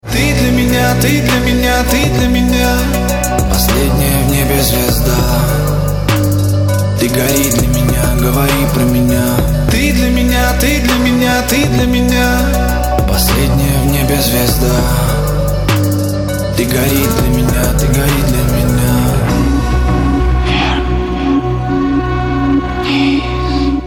• Качество: 320, Stereo
мужской вокал
лирика
Хип-хоп
грустные
русский рэп